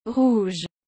Como pronunciar rouge corretamente?
O som correto é algo como “rruj”, com um “R” bem gutural, aquele bem típico do francês.
• O “R” precisa vir do fundo da garganta, meio raspando.
• O “ou” tem um som fechado, parecido com o “u” em “furo”.
• O “ge” soa como o “j” suave do português, tipo em “jorro”.